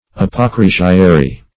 Search Result for " apocrisiary" : The Collaborative International Dictionary of English v.0.48: Apocrisiary \Ap`o*cris"i*a*ry\, Apocrisiarius \Ap`o*cris`i*a"ri*us\, n. [L. apocrisiarius, apocrisarius, fr. Gr.